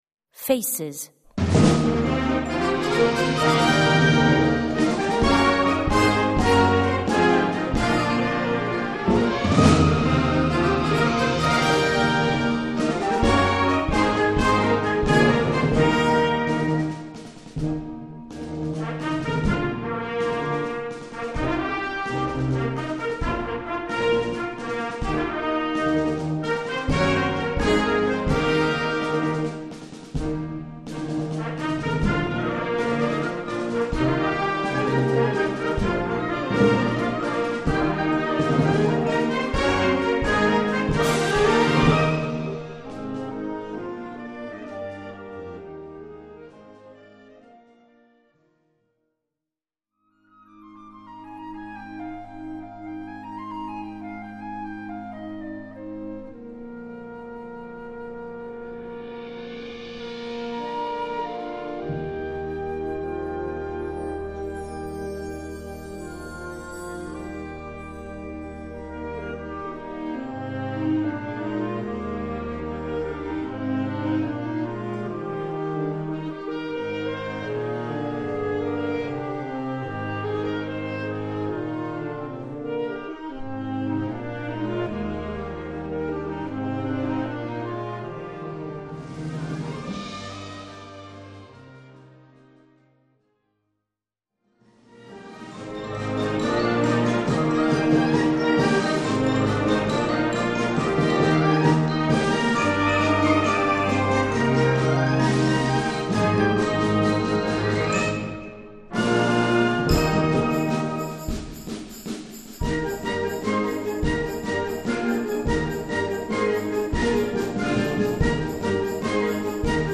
Répertoire pour Harmonie/fanfare - Fanfare